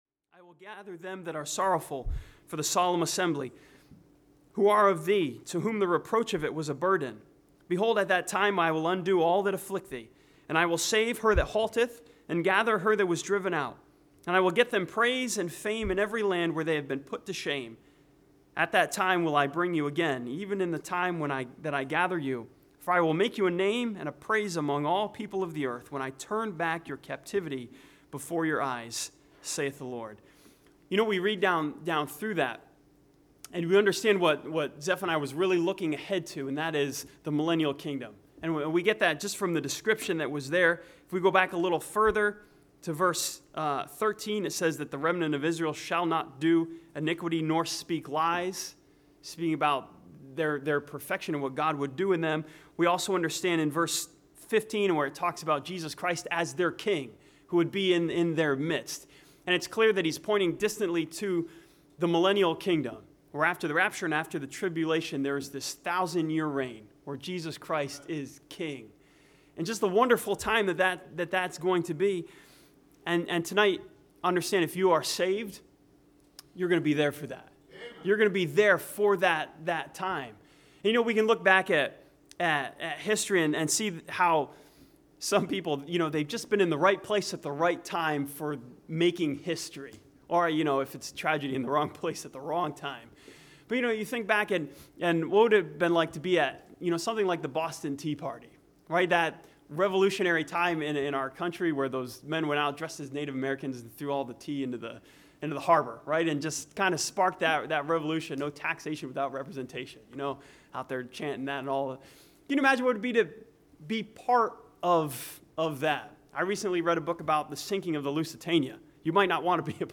This sermon from Zephaniah chapter 3 sees Jesus as the Gatherer who will bring all believers together into His millennial kingdom.